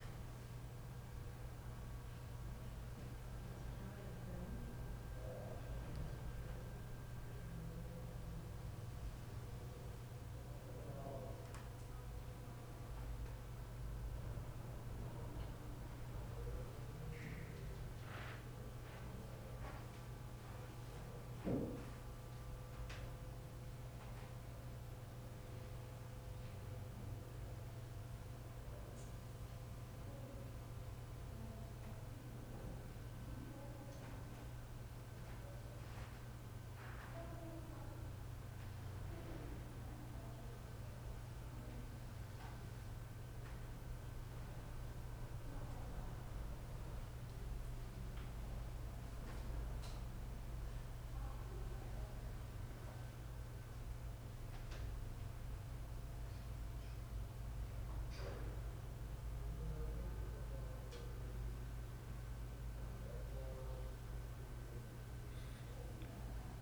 CSC-04-055-LE - Ambiencia sala com projetor e poucas pessoas longe.wav